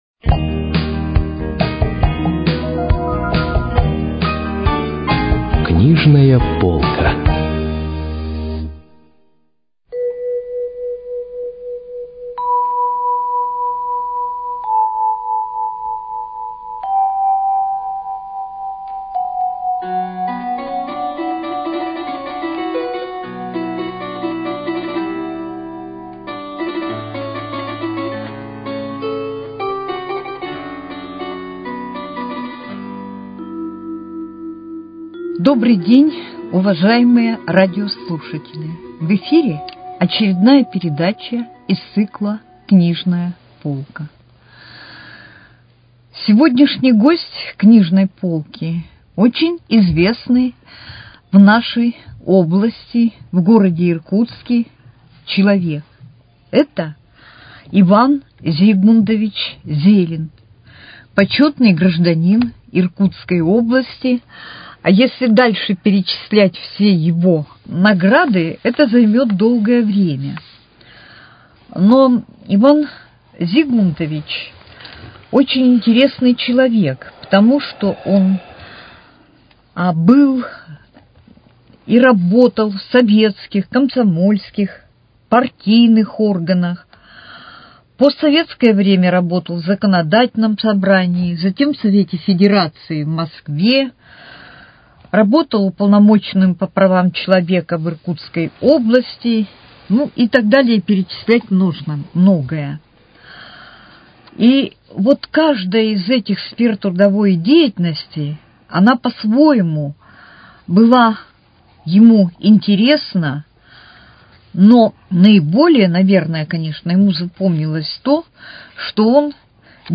Книжная полка: Беседа